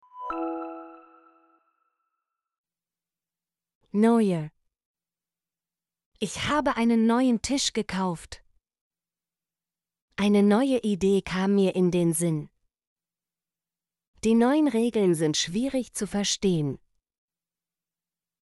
neuer - Example Sentences & Pronunciation, German Frequency List